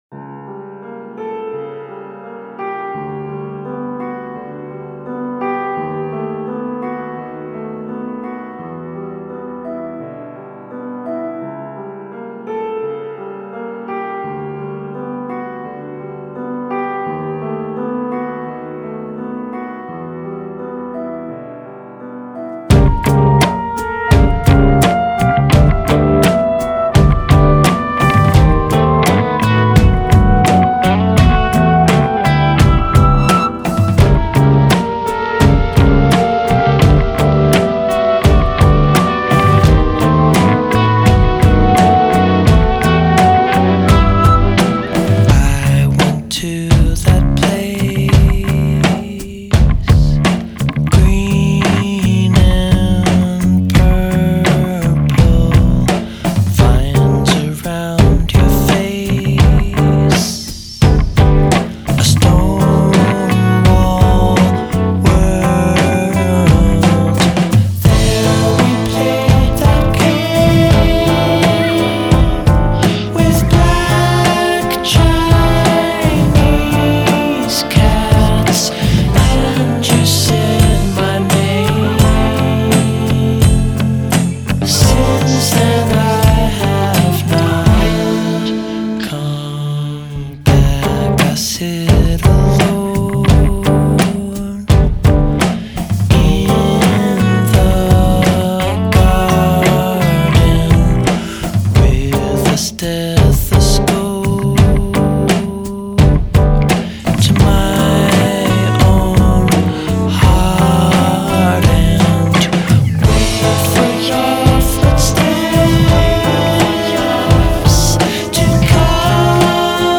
American pop group